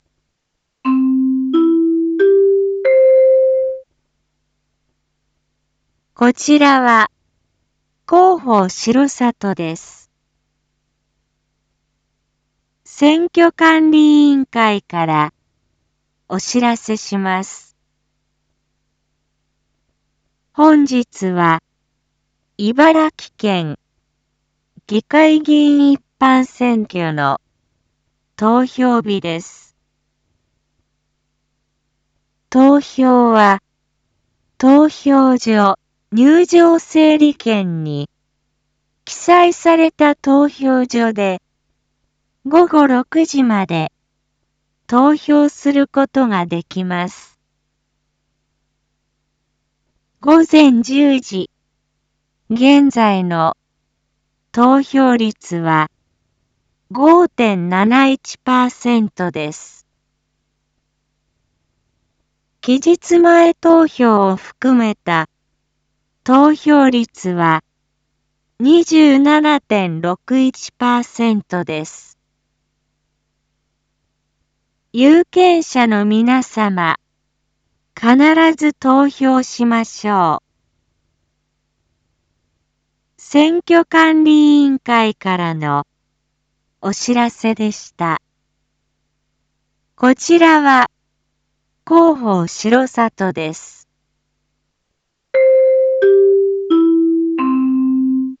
一般放送情報
Back Home 一般放送情報 音声放送 再生 一般放送情報 登録日時：2022-12-11 10:31:40 タイトル：県議会議員一般選挙（選挙速報）午前10時現在 インフォメーション：こちらは、広報しろさとです。